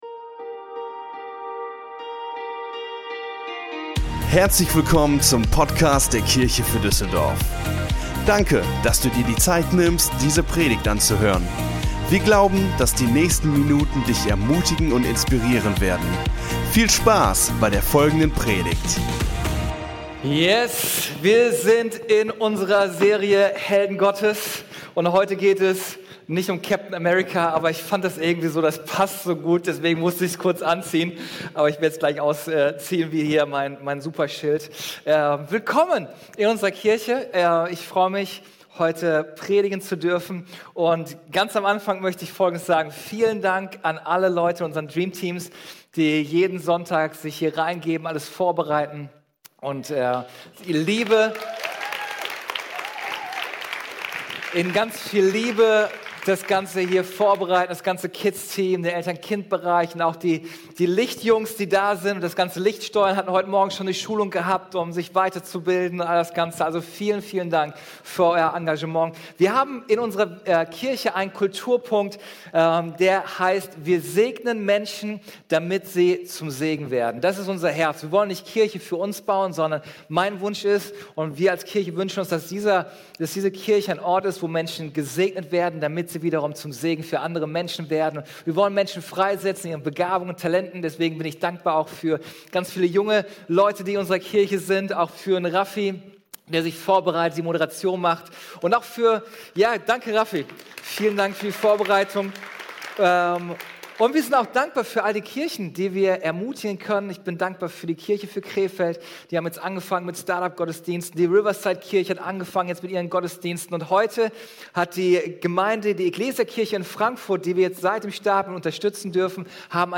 Der dritte Teil unserer Predigtserie: "Helden Gottes" Folge direkt herunterladen